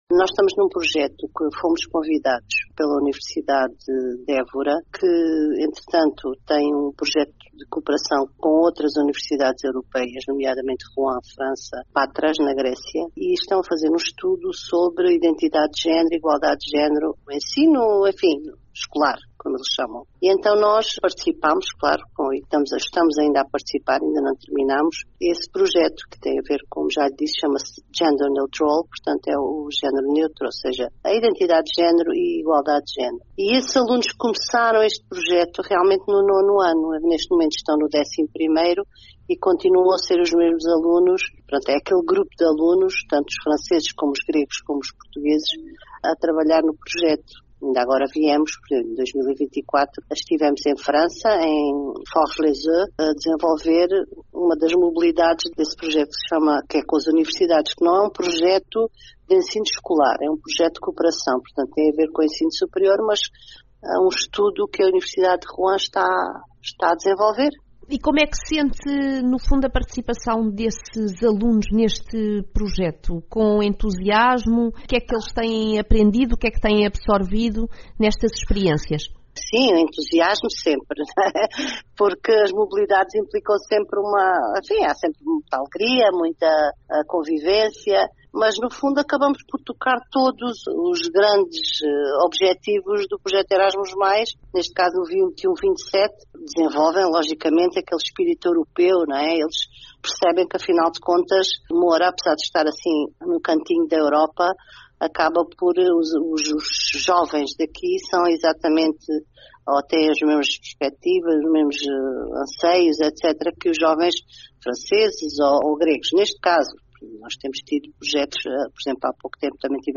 Entrevista Rádio Planicie